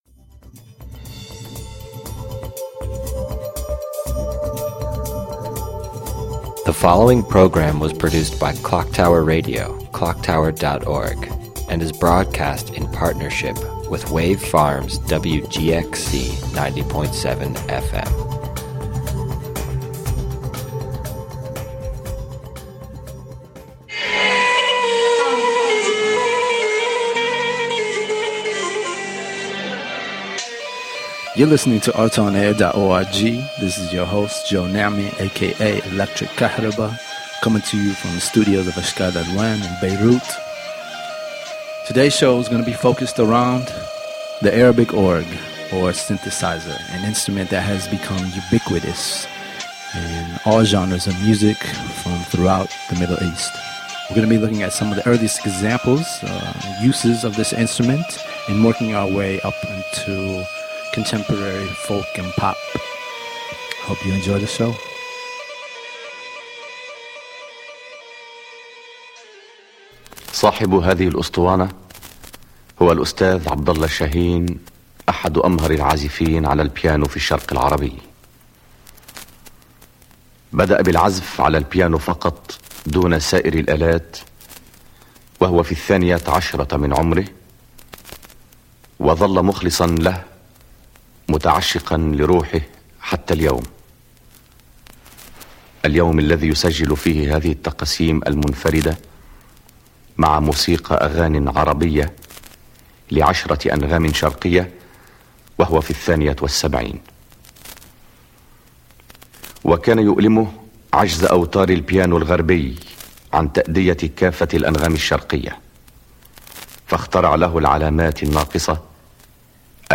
We'll hear some of the earliest examples of keyboard music and work our way up to the gritty amplified modern shaabi instrumentals of today.